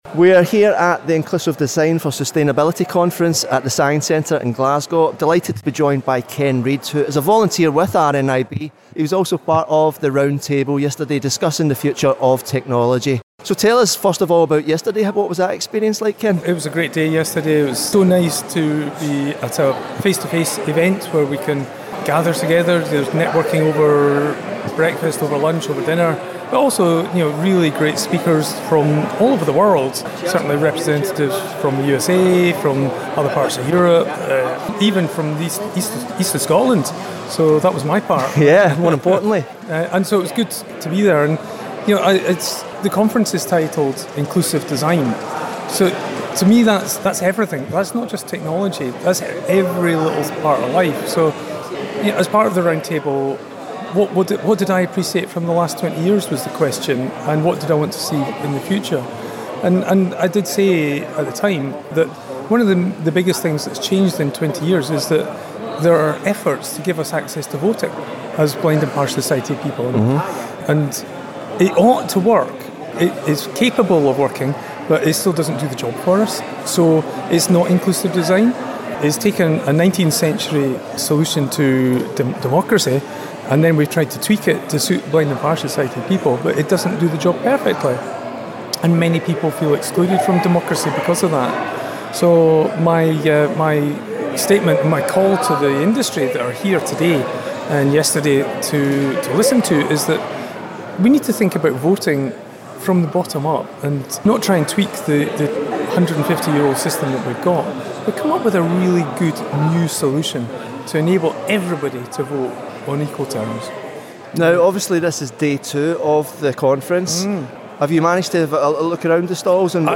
chats with one of the conference panellists